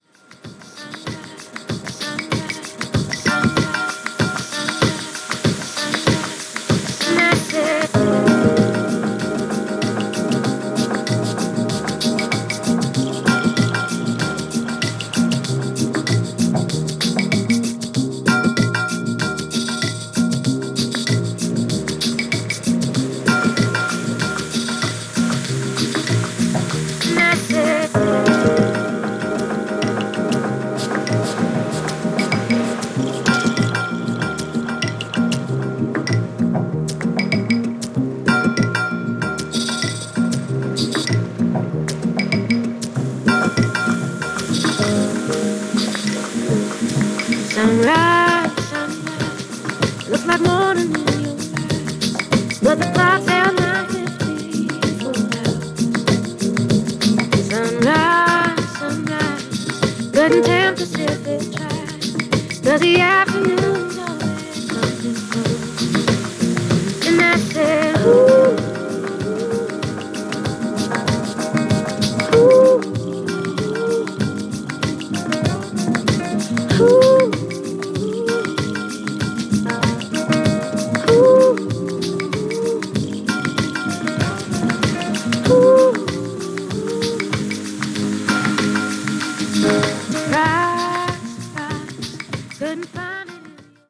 バレアリックな流れにピッタリなやつですね（笑）